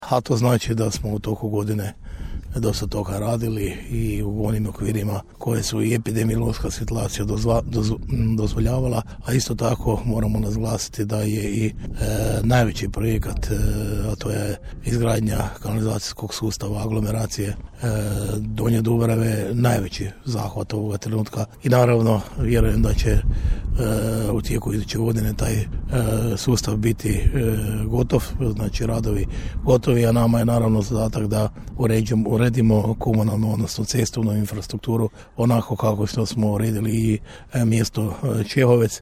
Gradonačelnik Ljubomir Kolarek u izjavi nam je istaknuo: